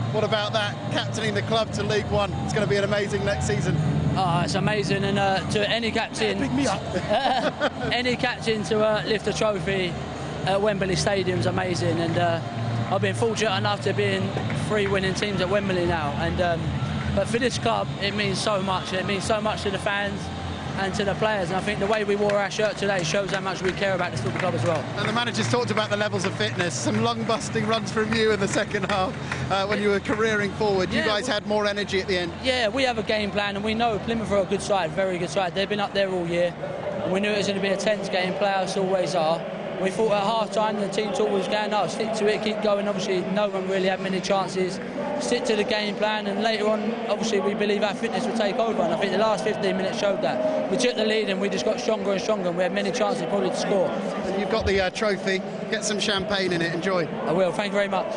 POST-MATCH